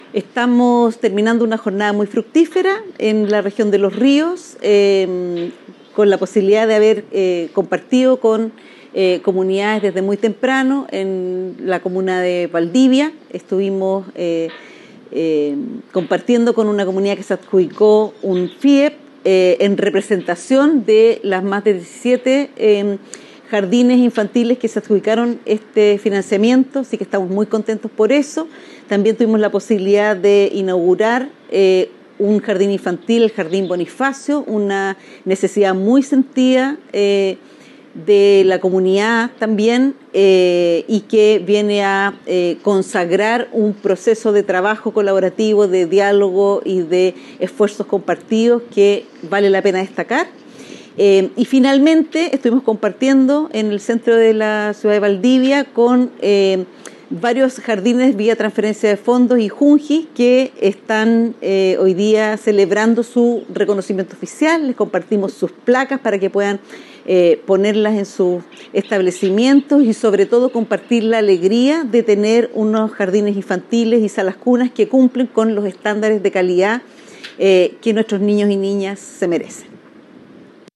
cuna-subsecretaria-por-visita-a-Los-Rios.mp3